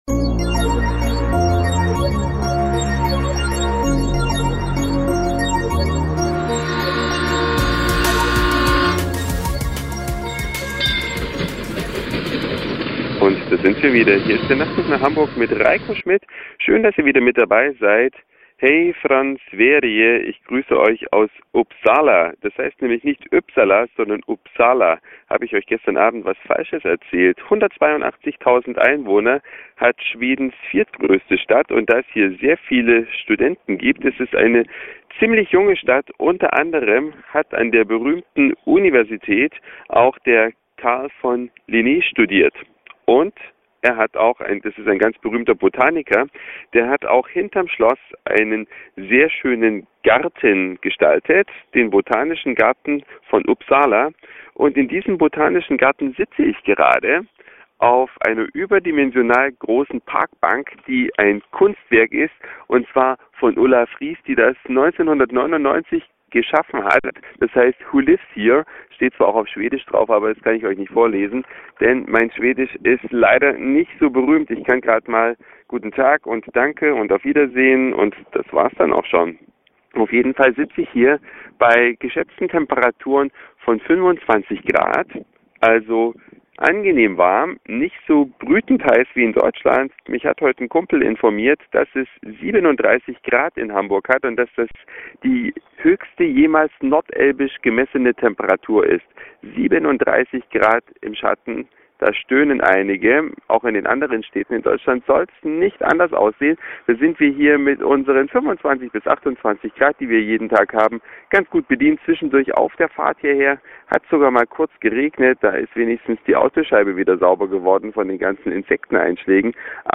Ein Podcast von der Riesenparkbank.